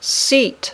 Haz clic en la palabra para escuchar su pronunciación y repetirla.